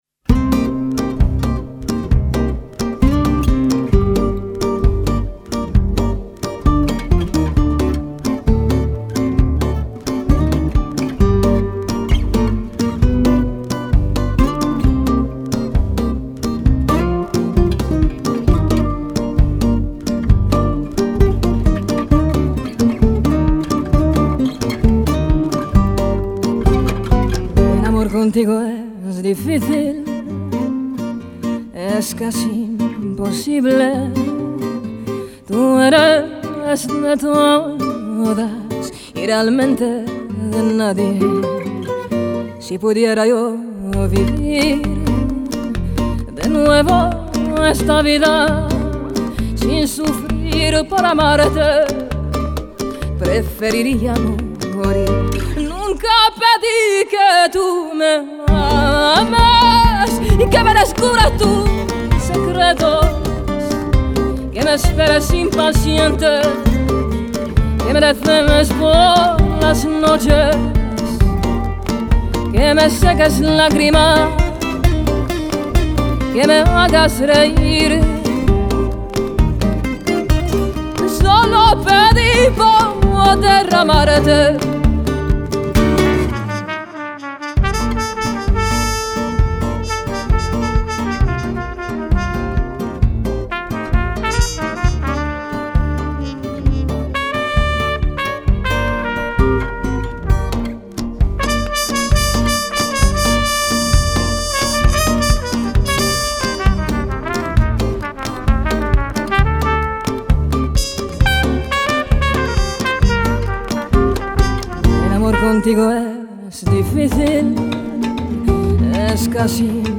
Genre: Ladino